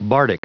Prononciation du mot bardic en anglais (fichier audio)